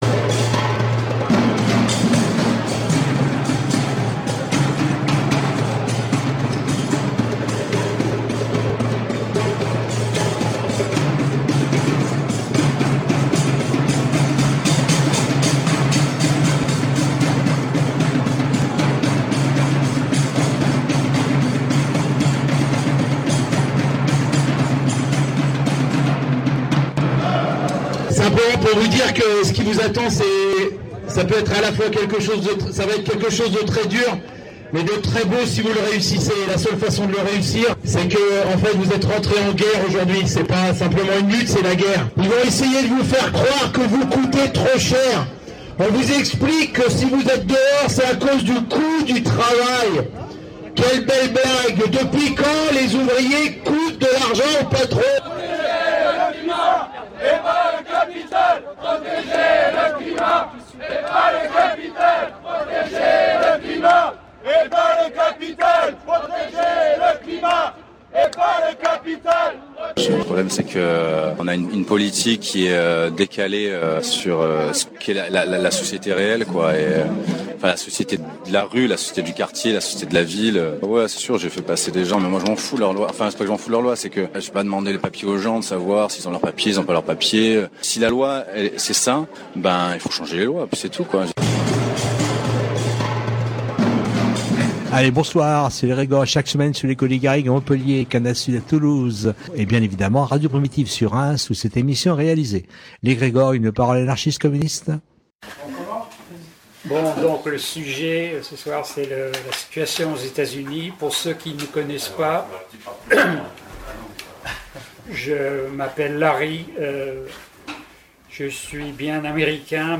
Ce soir, nous diffusons la 1ère partie du débat qui a eu lieu cet été aux Rencontres Libertaires du Quercy concernant la situation aux USA. Contrairement à la plupart des élections, celle de Trump a ouvert la voie à des bouleversements considérables.